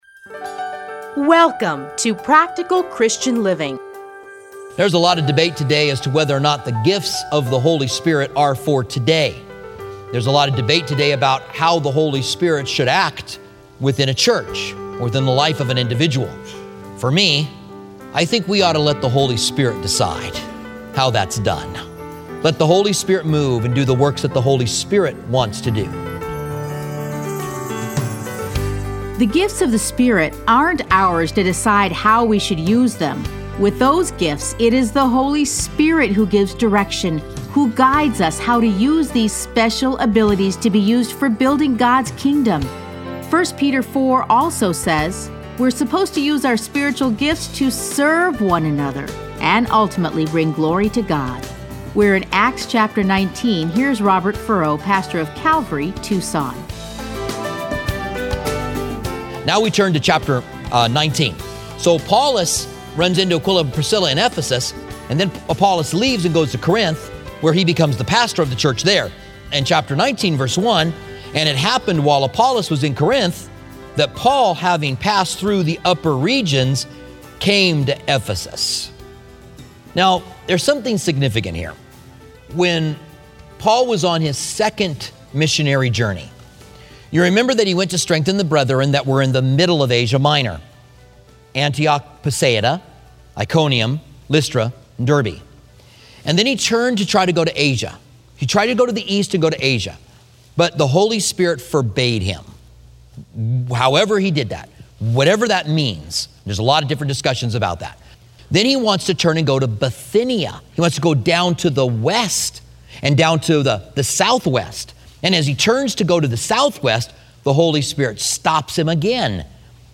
Listen to a teaching from Acts 18:24 to 19:20.